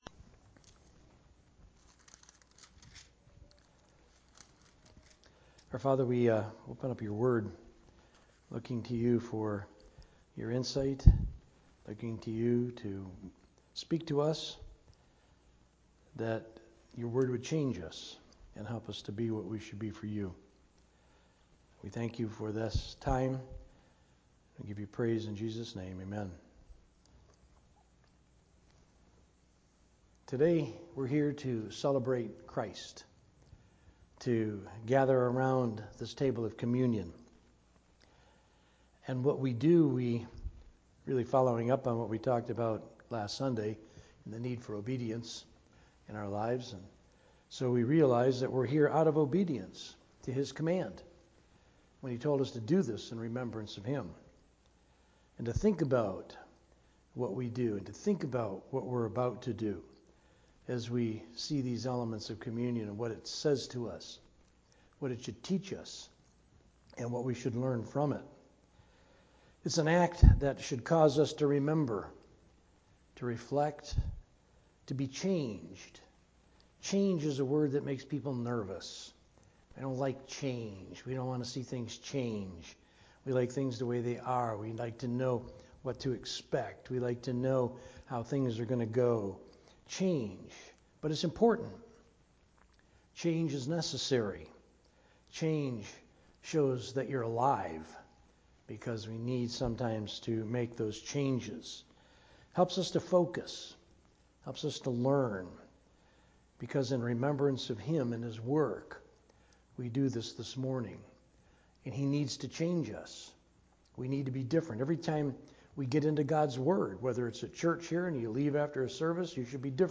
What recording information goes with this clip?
From Series: "Sunday Morning - 11:00"